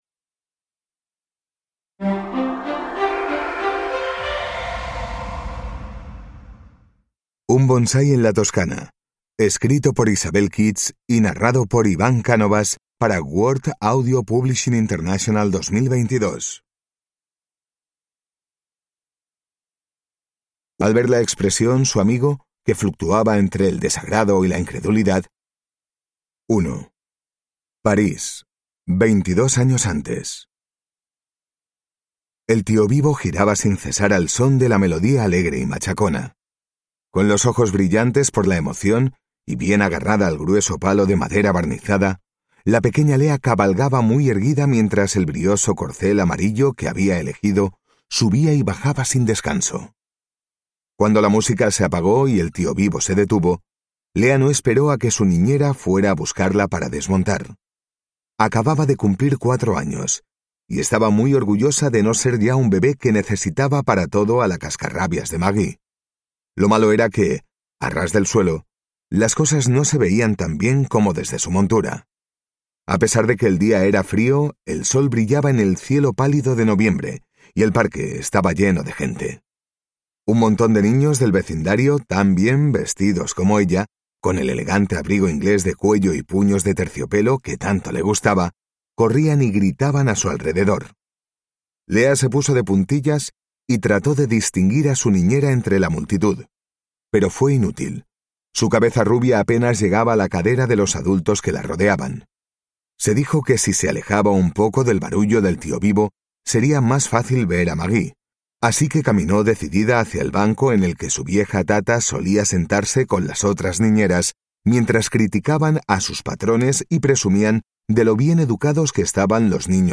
Un bonsái en la Toscana (ljudbok) av Isabel Keats